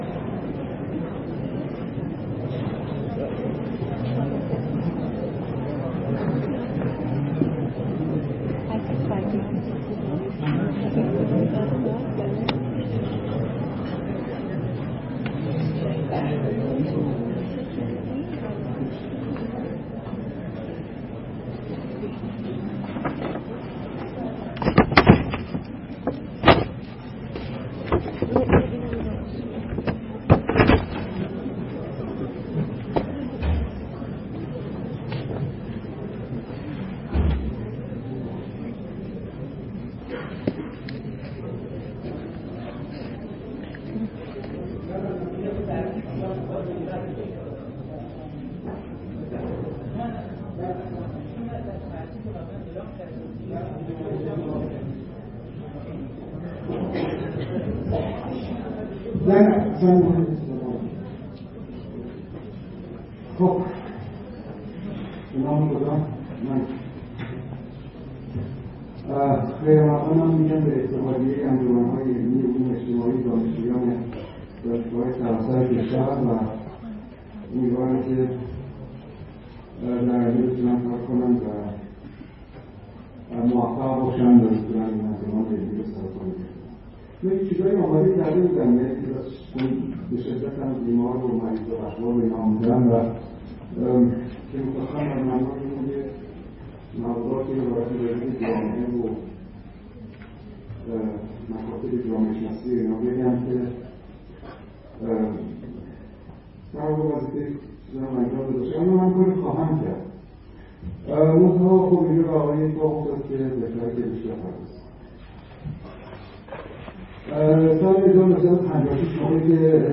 صوت/سخنرانی یوسف اباذری با عنوان «علوم اجتماعی و دانشگاه»
فرهنگ امروز: فایل حاضر سخنرانی یوسف اباذری در اولین مجمع اتحادیه انجمن‌های علمی دانشجویی علوم اجتماعی کشور با عنوان «علوم اجتماعی و دانشگاه» است که دوشنبه ۲۲ فروردین ۱۳۹۵ در تالار ابن خلدون دانشکدۀ علوم اجتماعی دانشگاه تهران برگزار شد.